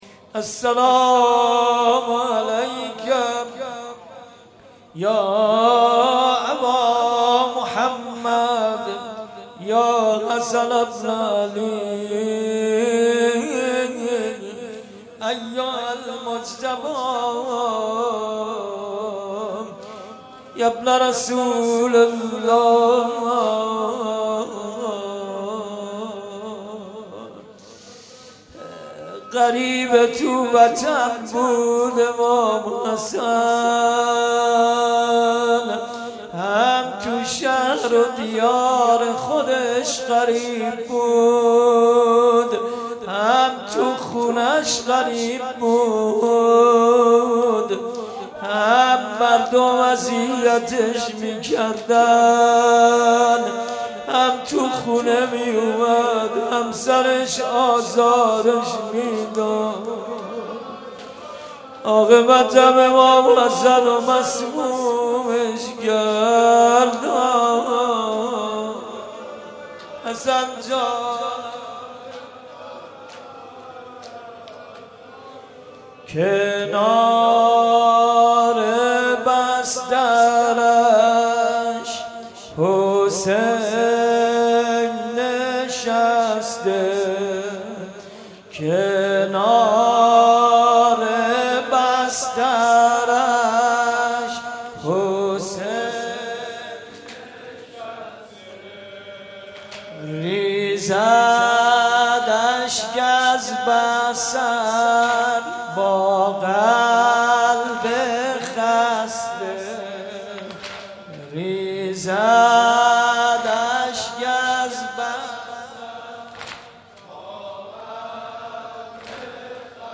مراسم پرفیض قرائت دعای کمیل
در مسجد شهدا واقع در اتوبان محلاتی برگزار شد.